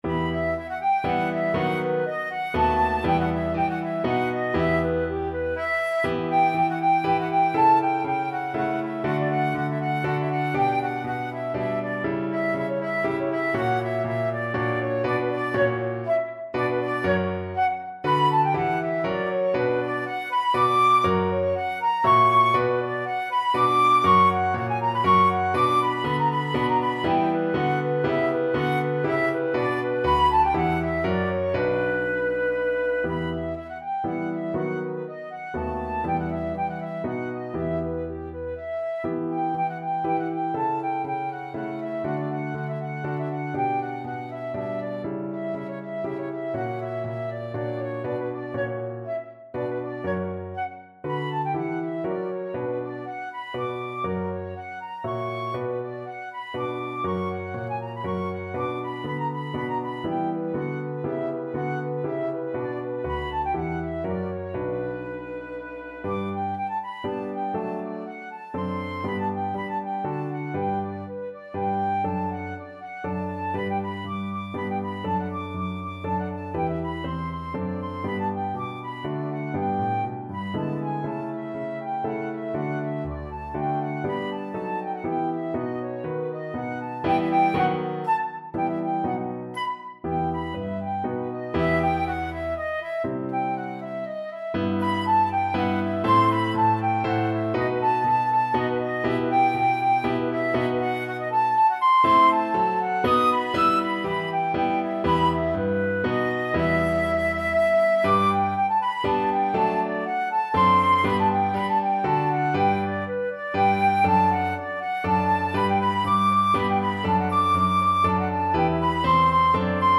Flute
3/8 (View more 3/8 Music)
G5-E7
E minor (Sounding Pitch) (View more E minor Music for Flute )
=120 Vivace (View more music marked Vivace)
Classical (View more Classical Flute Music)